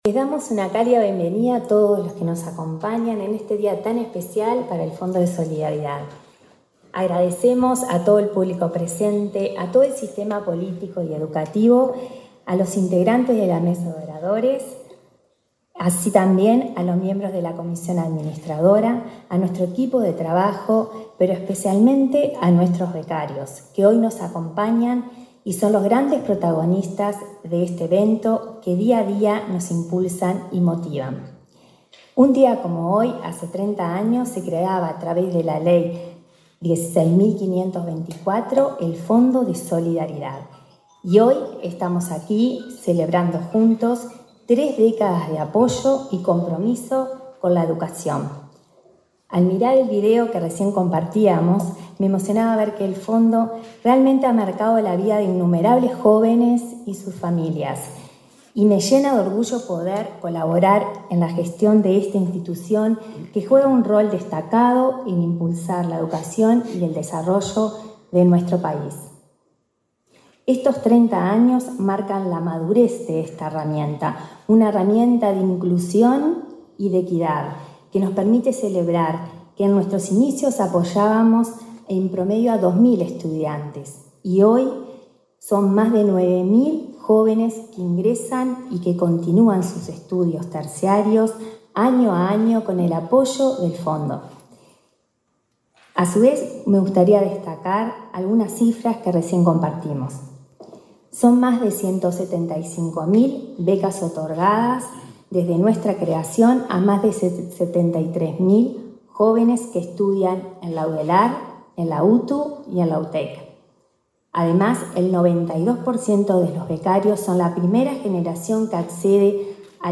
Palabras de autoridades en acto aniversario del Fondo de Solidaridad
Palabras de autoridades en acto aniversario del Fondo de Solidaridad 25/07/2024 Compartir Facebook X Copiar enlace WhatsApp LinkedIn La presidenta del Fondo de Solidaridad, Rosario Cerviño, y el ministro de Educación y Cultura, Pablo da Silveira, participaron en el acto conmemorativo de los 30 años de esa repartición.